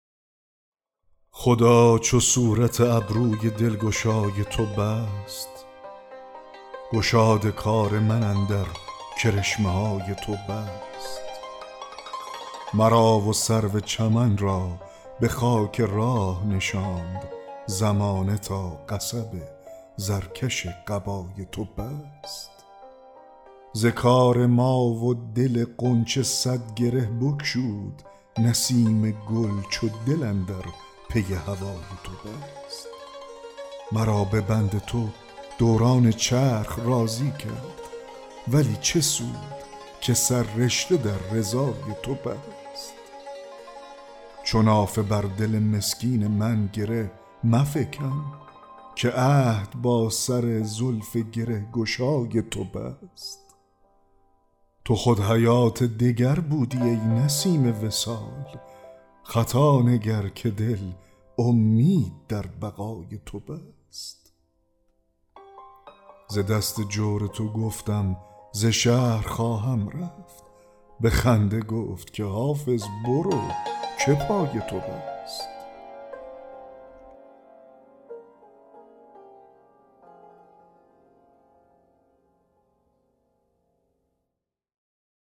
دکلمه غزل 32 حافظ